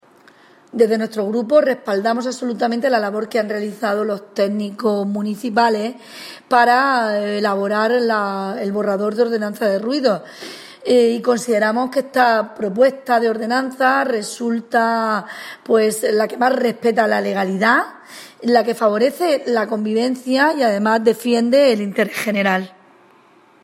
CTSSP - Podemos en la Comisión de Urbanismo